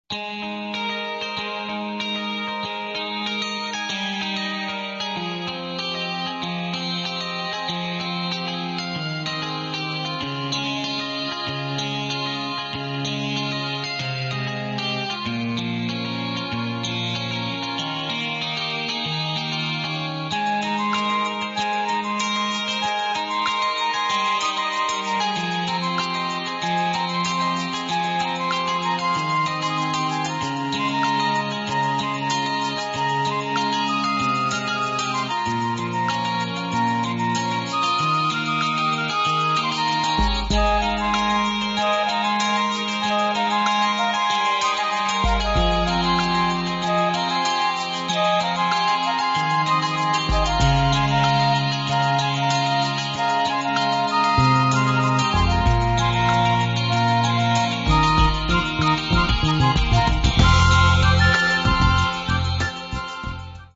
• ファイルサイズ軽減のため、音質は劣化しています。
シンセサイザー演奏